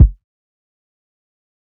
SouthSide Kick (13).wav